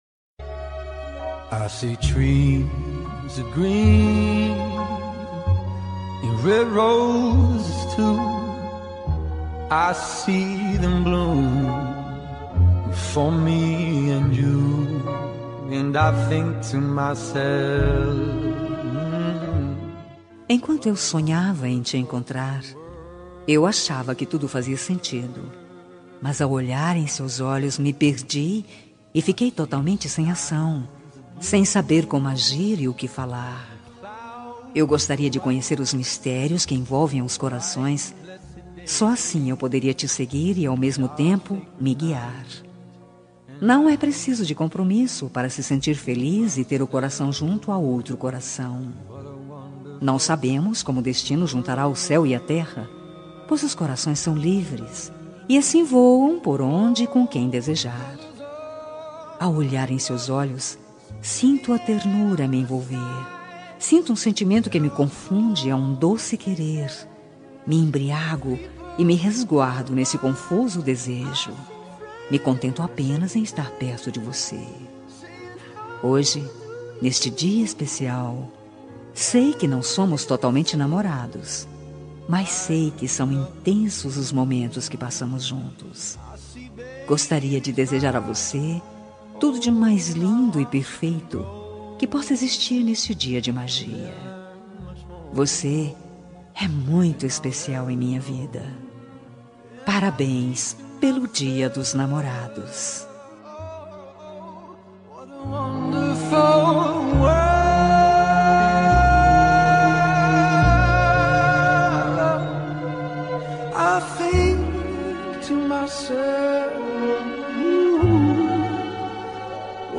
Dia dos Namorados – Para Namorado – Voz Feminina – Cód: 6860